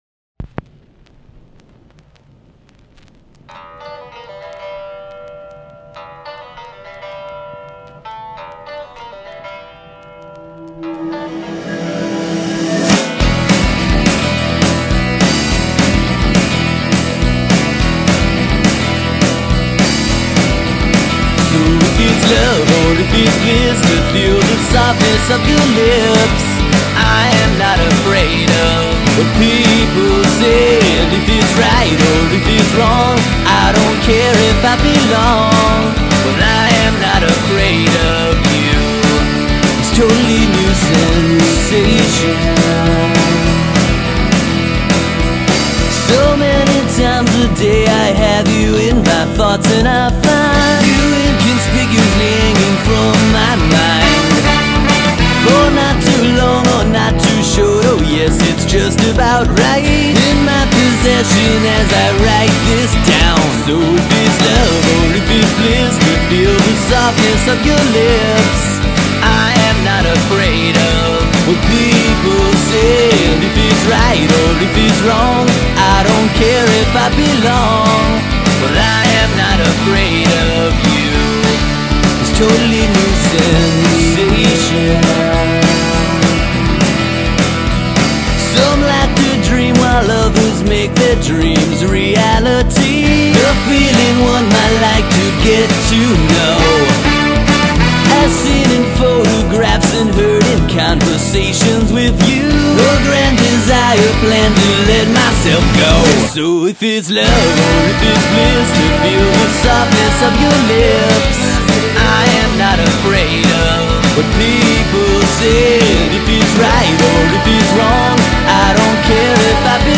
Vocals, acoustic guitar
Electric guitars, bass guitar, synth, drums
Horns